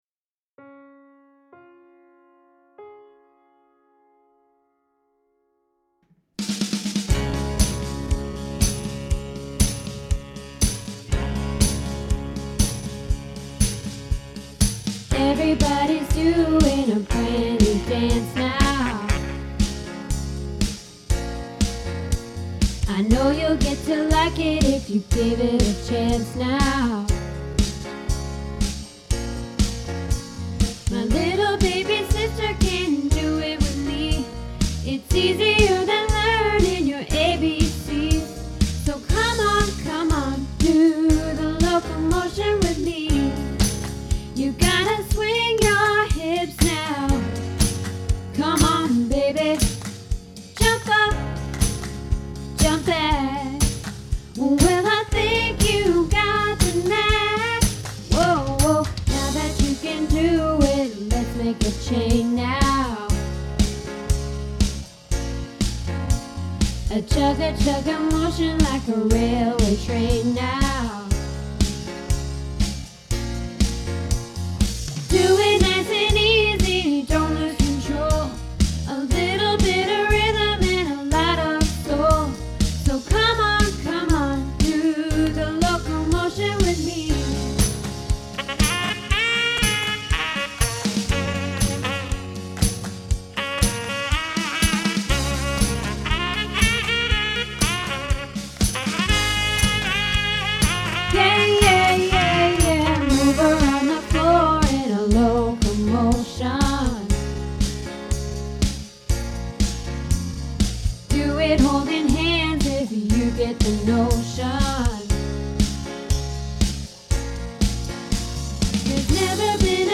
Locomotion - Alto